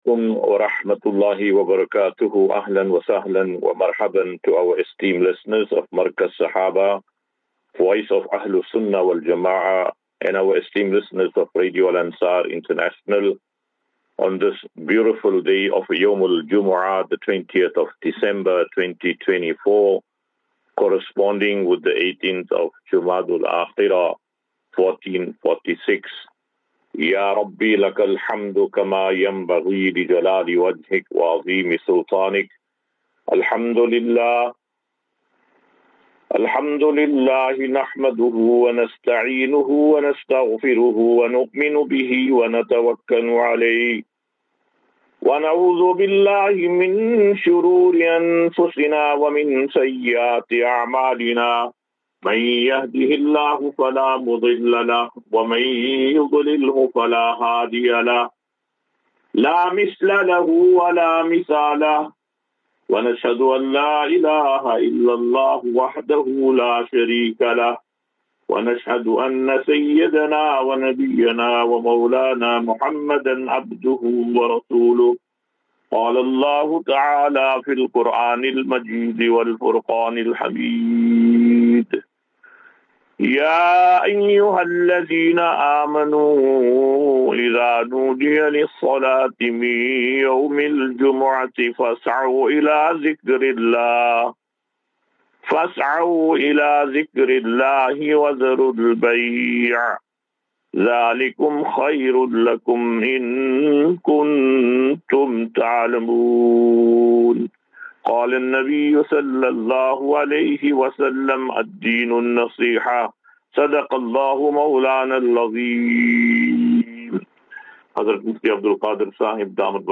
Assafinatu - Illal - Jannah. QnA